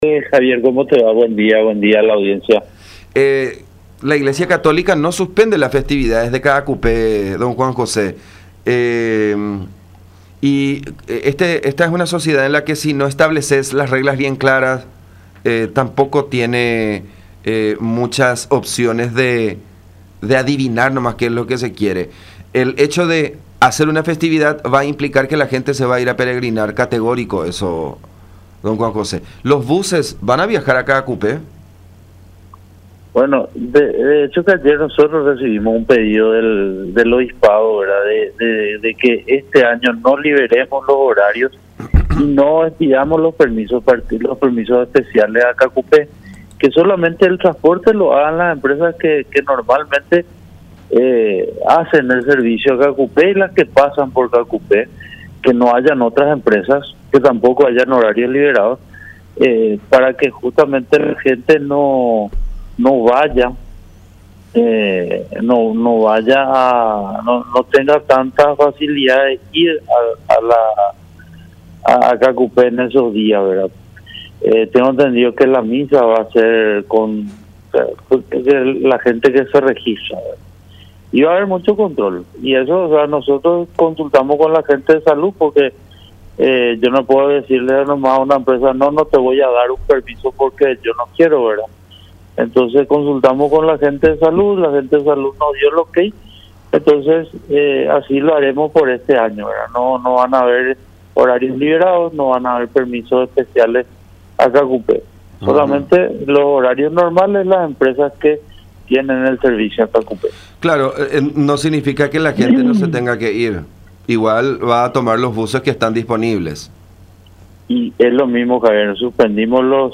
“Recibimos un pedido del obispado ayer de no liberar horarios y otorgar permisos especiales para estas festividades a los efectos de que la gente no vaya en gran número a Caacupé. Para ello, existirán estrictos controles y así lo haremos al menos este año”, explicó Juan José Vidal, titular de DINATRAN, en conversación con La Unión.